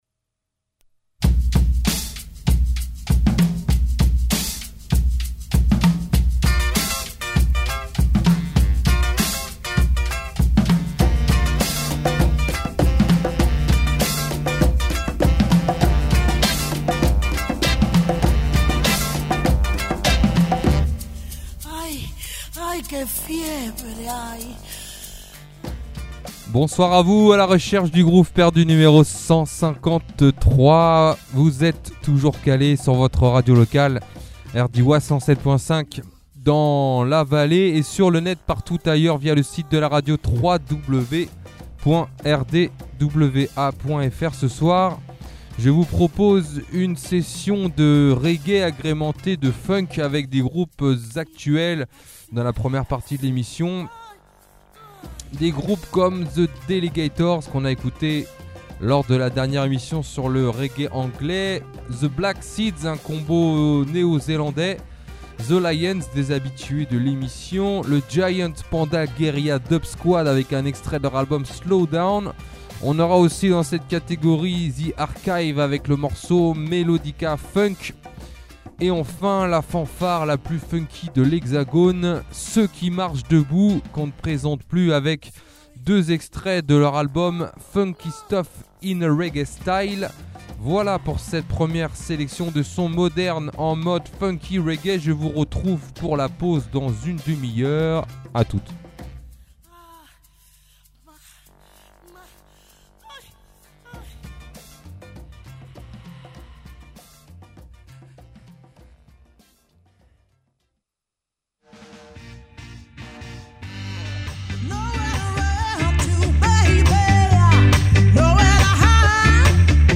100% reggunk Publié le 14 avril 2015 Partager sur…
funk , musique , reggae